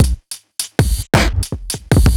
Index of /musicradar/off-the-grid-samples/110bpm
OTG_Kit8_Wonk_110a.wav